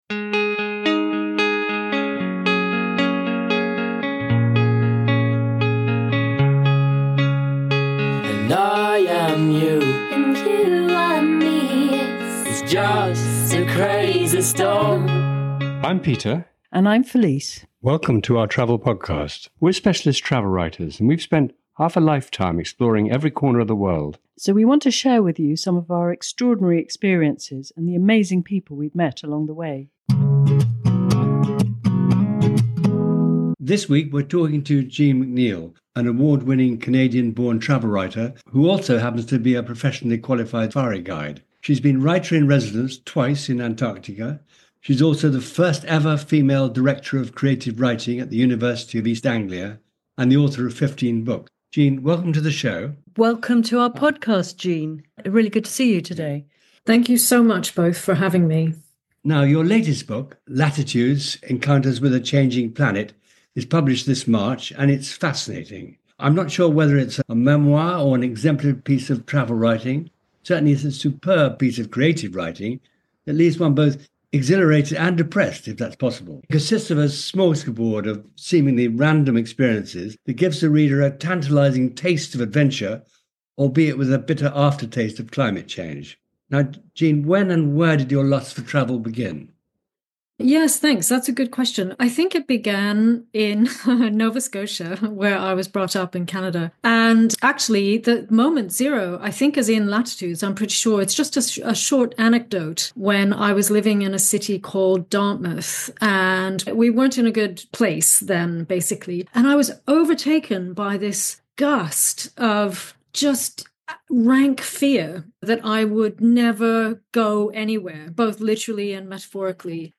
Listen below or click here for the full interview, and be sure to check out Action Packed Travel’s other fantastic interviews.